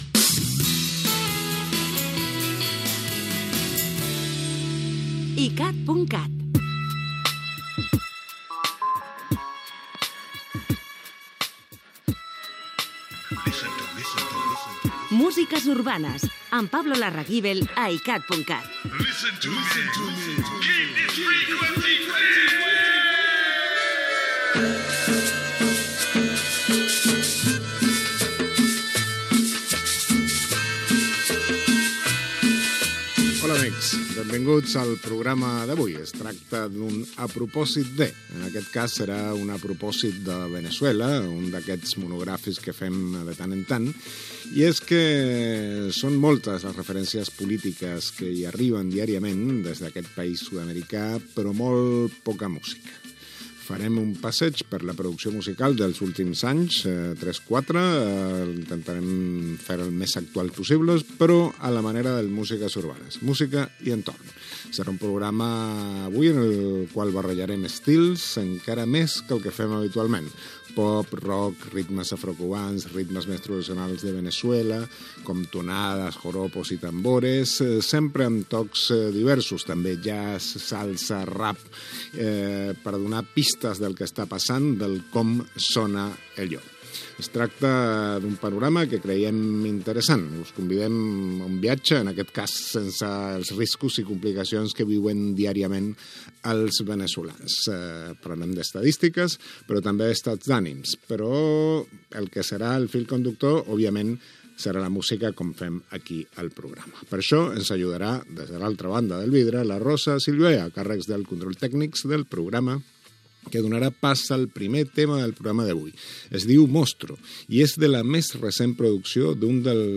Careta del programa, la producció de música urbana a Veneçuela
Musical